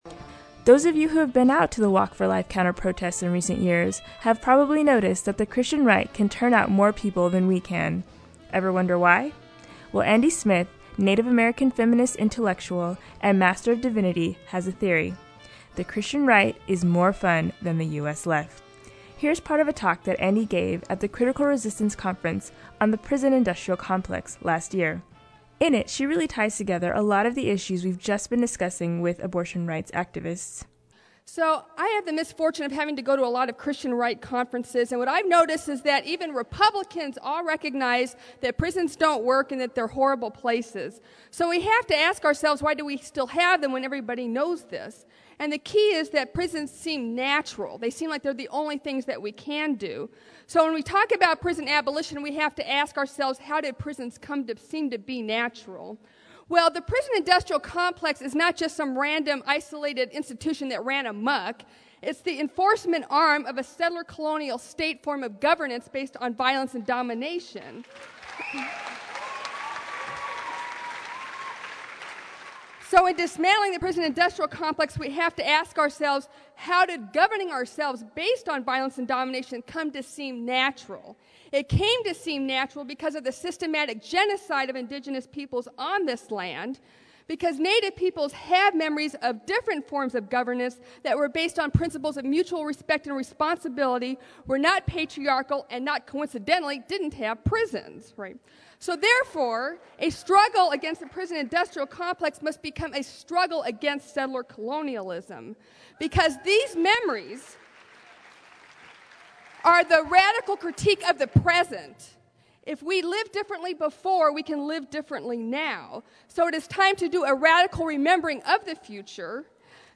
This talk was given at Critical Resistance 10, a conference on opposing the prison industrial complex, held last fall in Oakland.